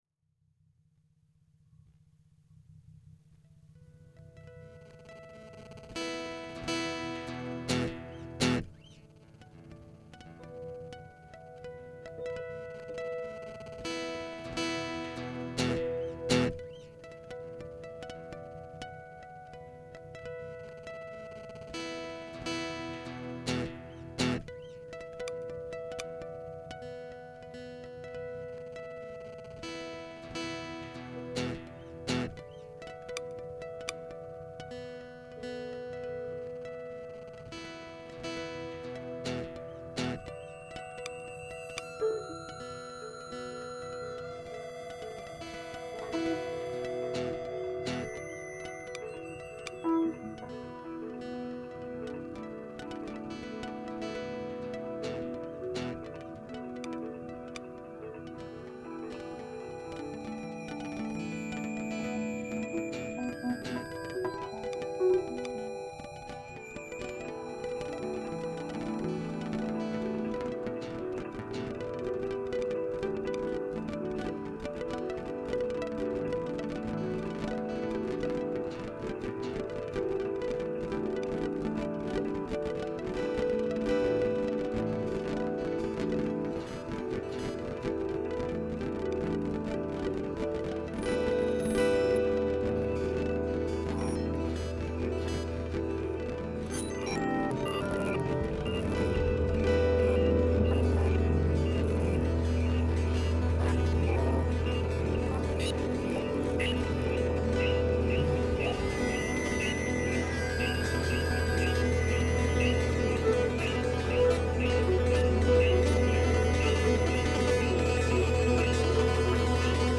laptop
guitar